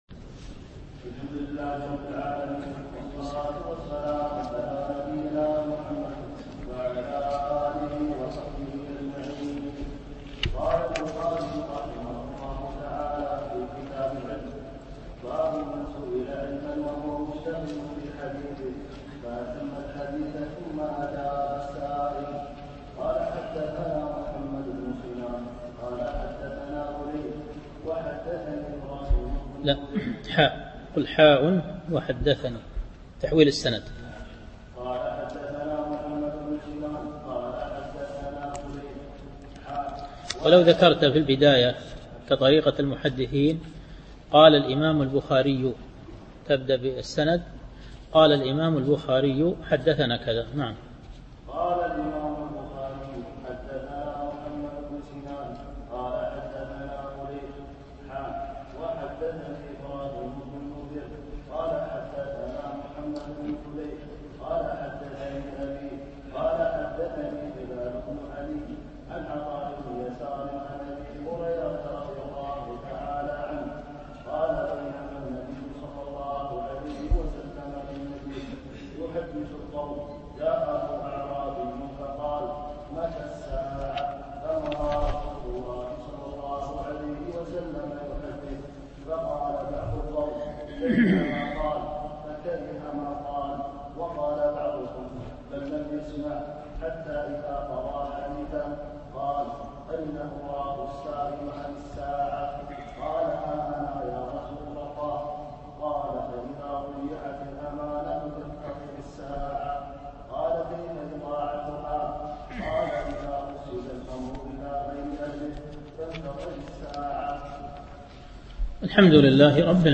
دروس مسجد عائشة
التنسيق: MP3 Mono 22kHz 32Kbps (VBR)